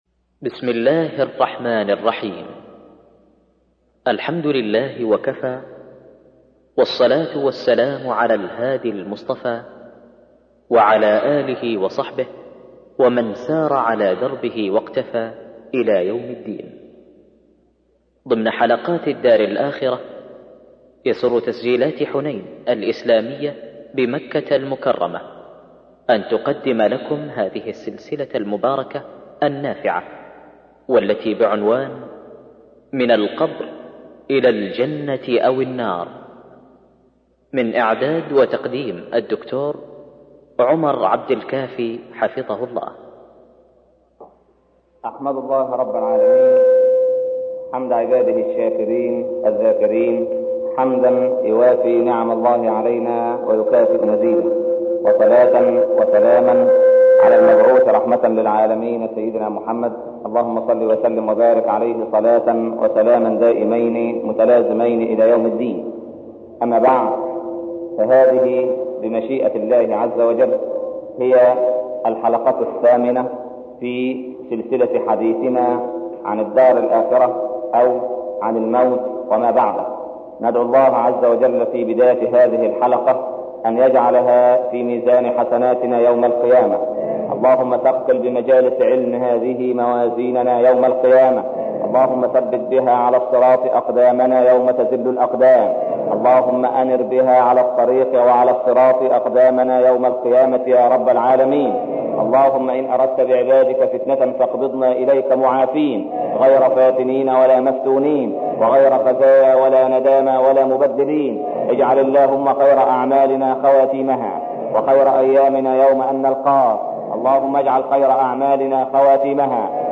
الدرس الثامن - الدار الآخرة - الشيخ عمر بن عبدالكافي